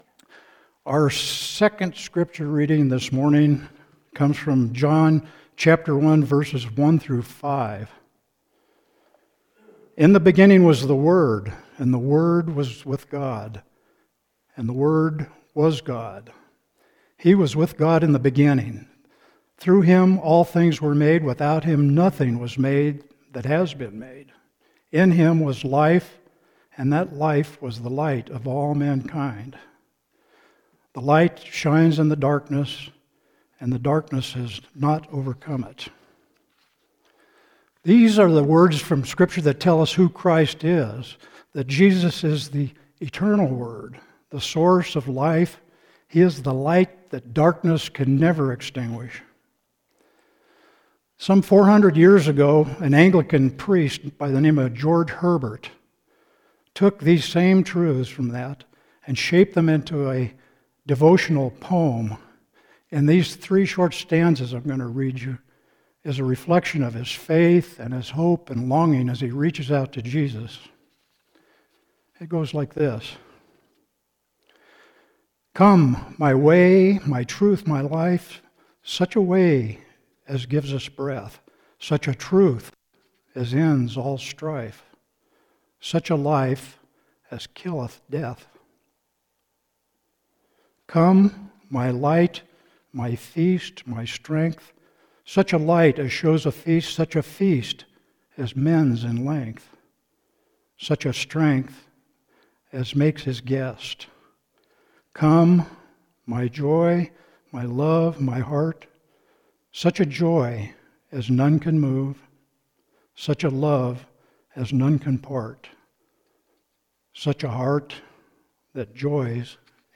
Sermon – April 19, 2026 – “Love Lived Here”